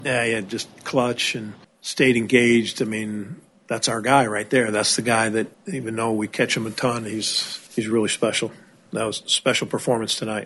Brewers manager Pat Murphy on William Contreras (4×4 with 2rbi).